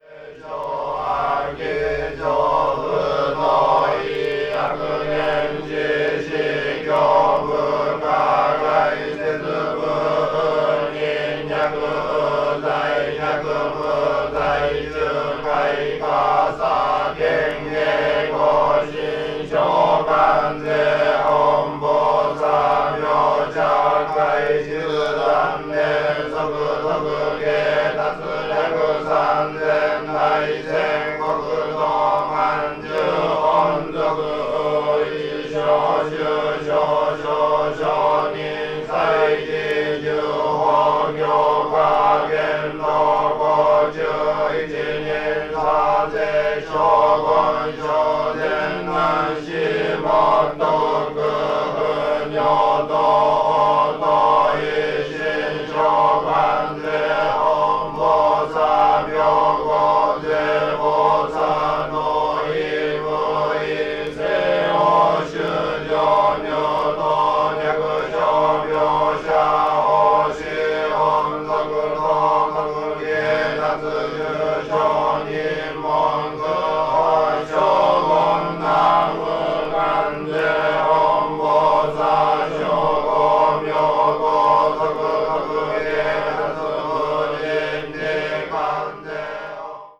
20th century   contemporary   field recording   non music   orchestra   post modern   spoken word